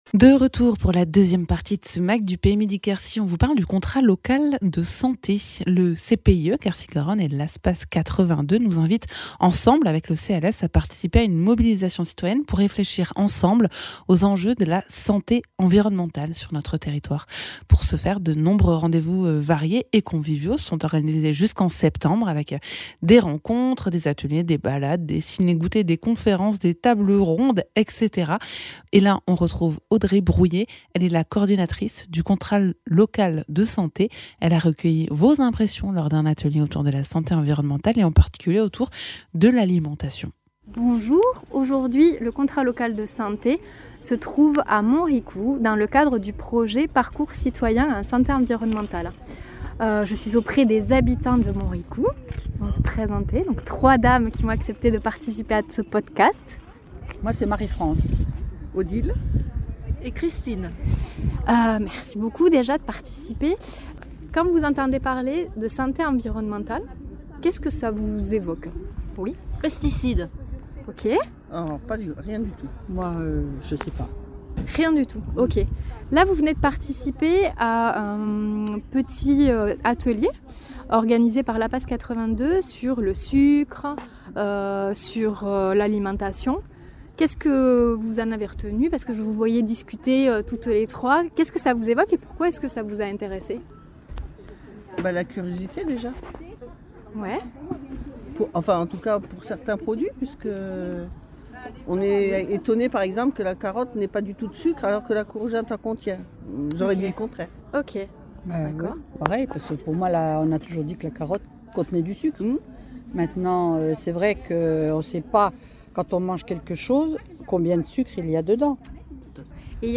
On parle santé environnementale avec le Contrat Local de santé du PETR Midi-Quercy : témoignages à Montricoux dans le cadre d’un parcours citoyen.